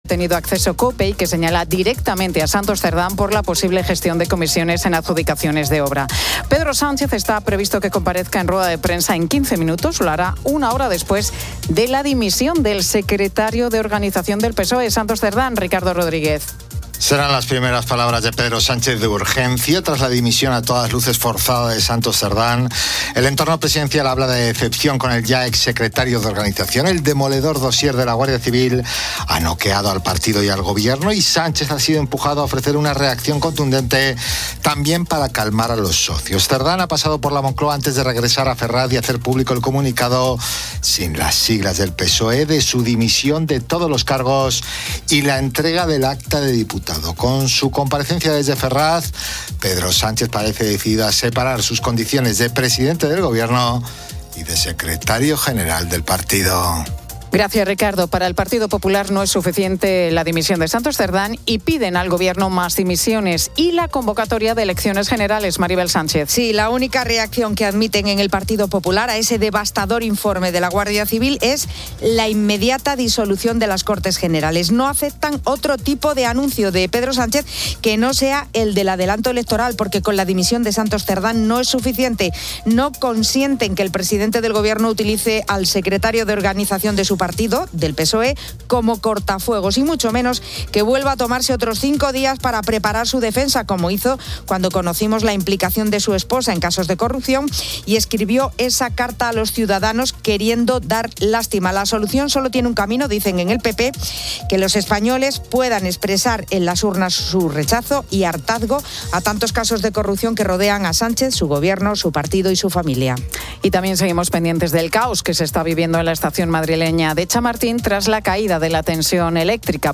La Tarde 17:00H | 12 JUN 2025 | La Tarde Pilar García Muñiz y el equipo de La Tarde siguen en directo la comparecencia del presidente del Gobierno, Pedro Sánchez, tras la dimisión de Santos Cerdán como secretario de Organización del PSOE.